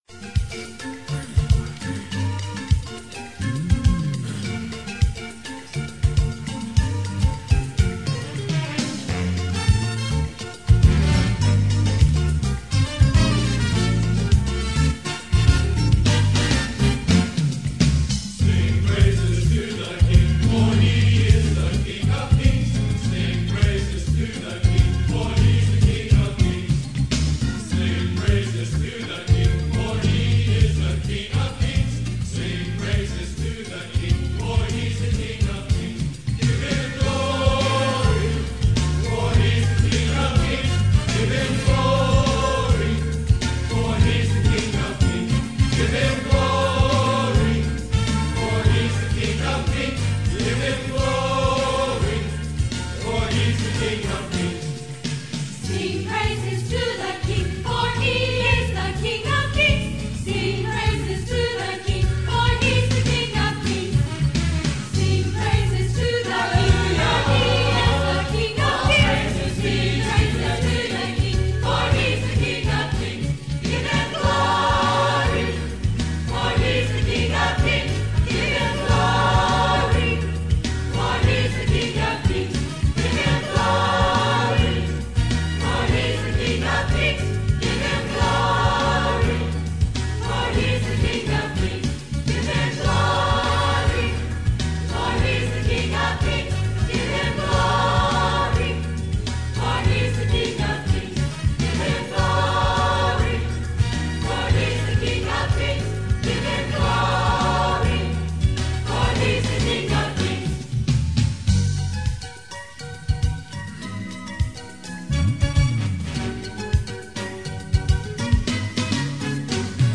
Songs from Calvary Baptist Church